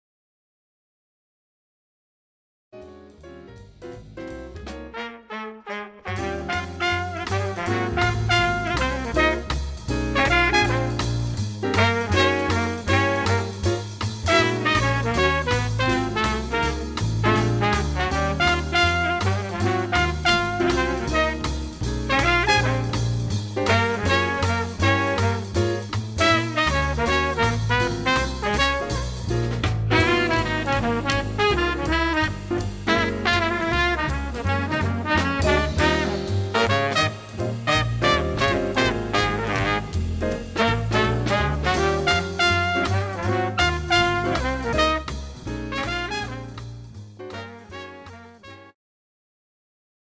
The Best In British Jazz
Recorded at Clowns Pocket Studio, London 2013